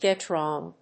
gèt…wróng